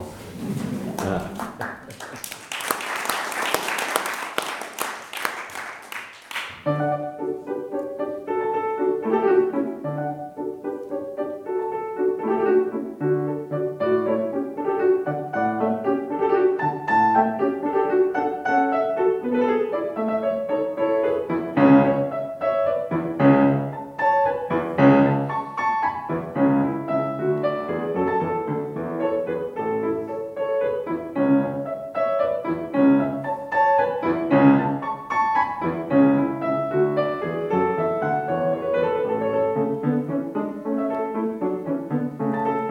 Concerto
Sonata in re maggiore K 430 - Non presto, ma a tempo di ballo